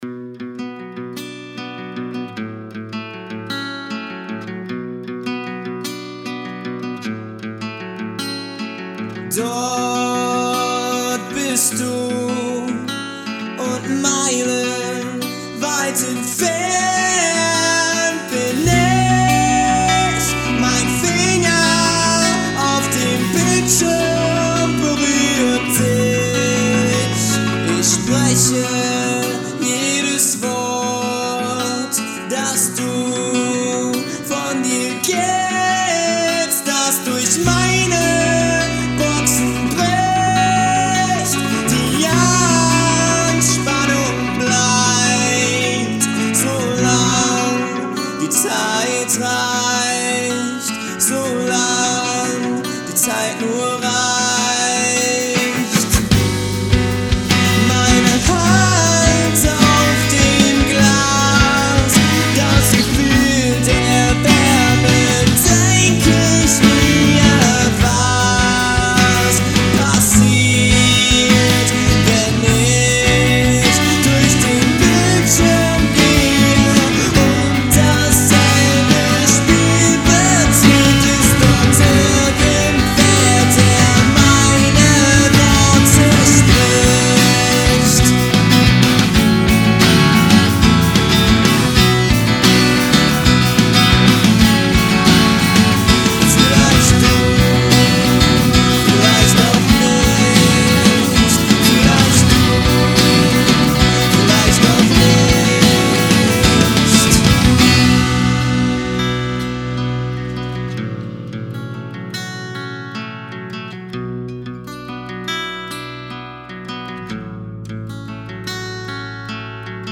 Neuer Song ohne Vocals
wir geht es ums Mixing und Mastering ... ich hab das jetzt auf verschieden Boxen gehört und hab natürlich die unterschiedlichsten Ergebnisse ..insgesamt scheint es mir zu overcompressed ...was meint ihr bedanke mich für Feedback im Voraus